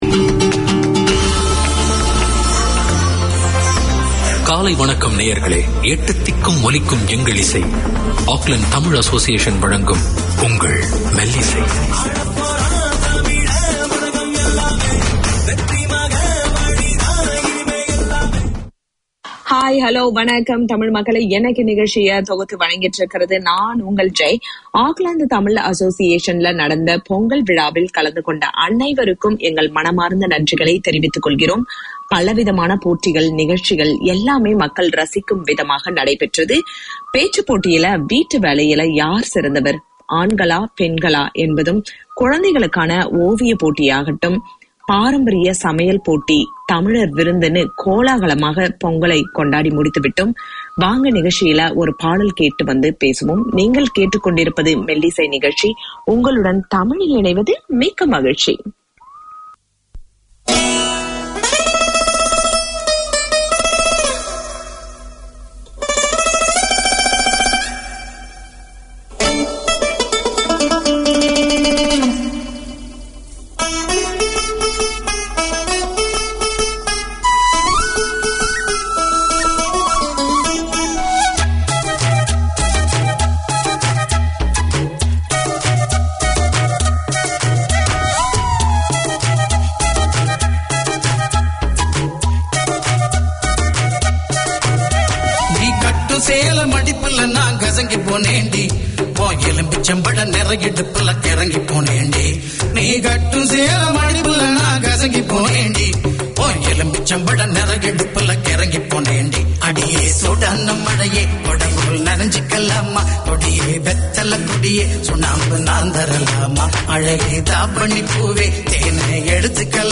Tamil literature, poems, news and interviews, current affairs, wit and humour. Varied music, entertaining topics.